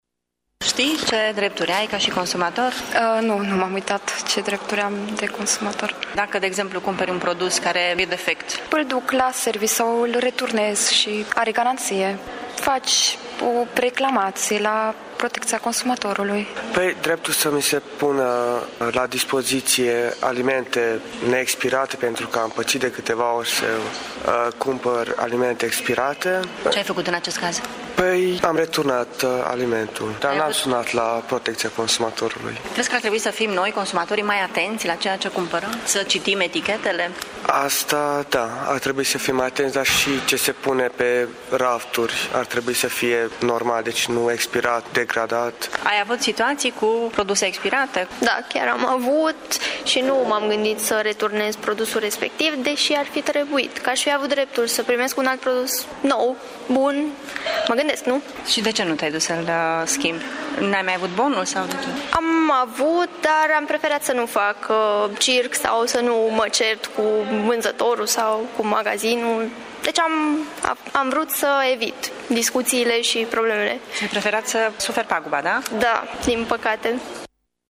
Unii tineri târgumureșeni recunosc că nu știu ce drepturi au și au preferat să suporte paguba unui produs cumpărat defect decât să reclame :